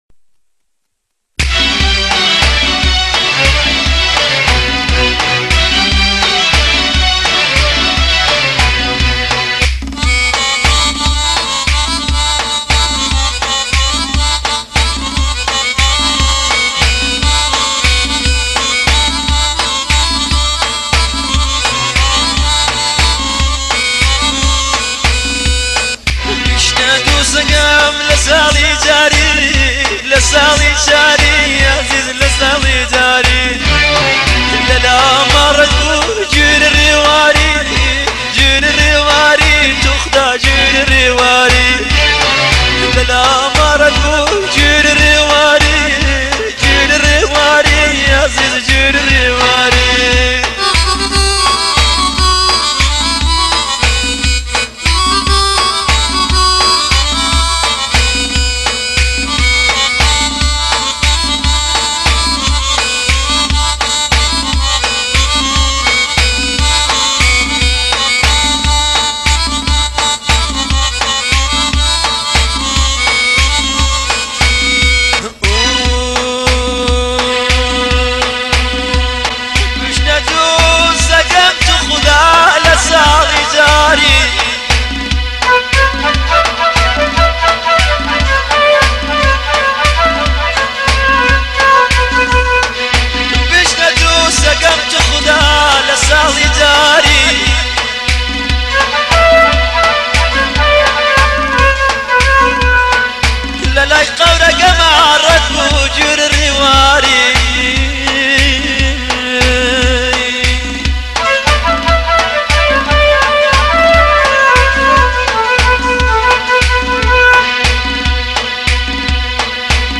اهنگ غمگین کردی
اهنگ کردی غمگین